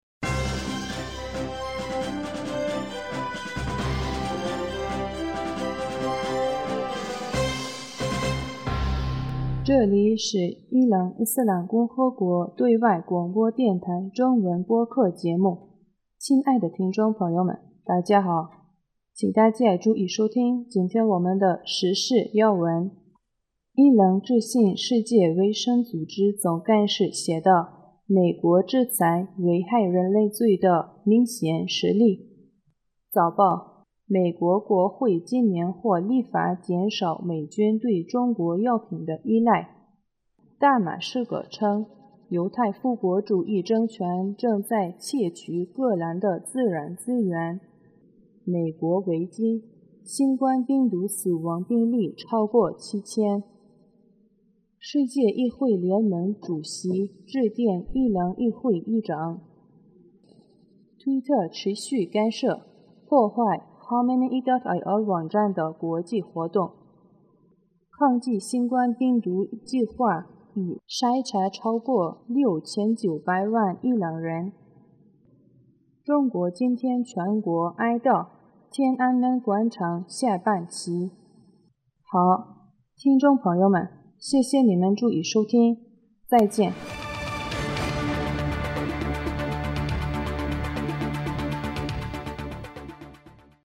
2020年 4月 04日 新闻